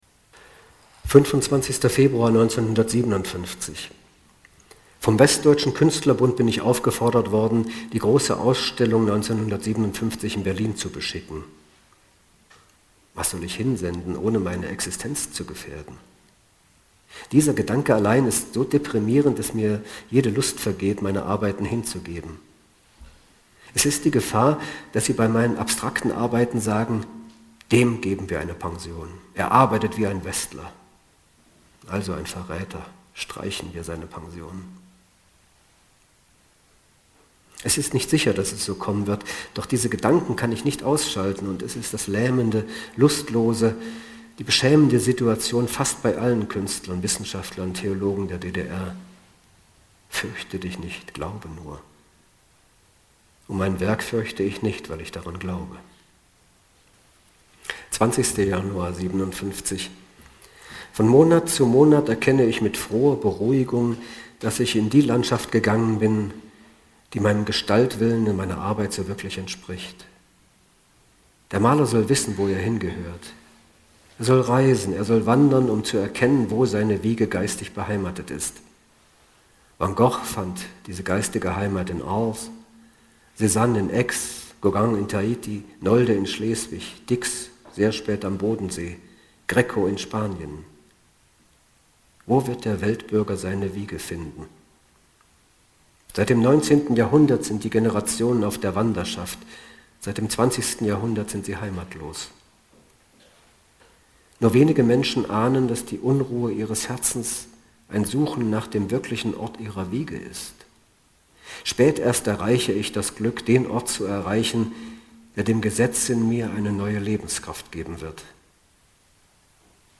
Der Theatersaal war bis auf den letzten Platz gefüllt.
Lesung